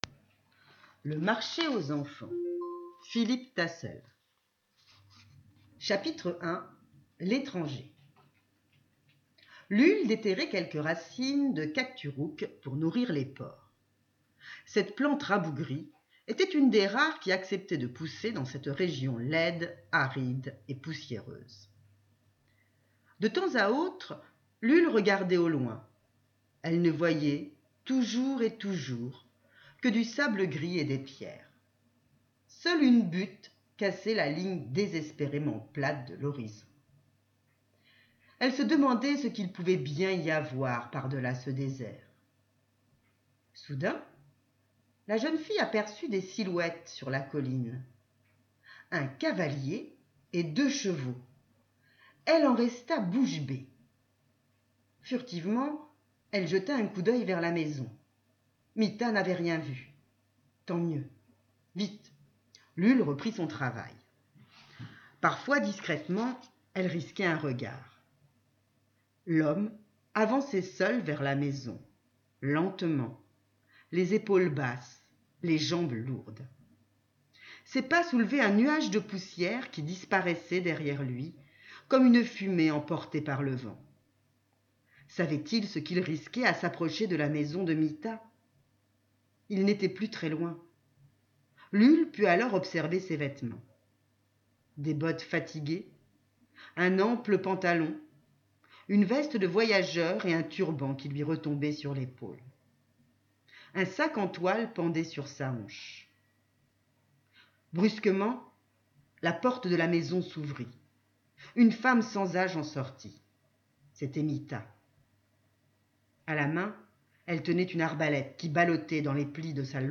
Lecture du chapitre 1 par la maîtresse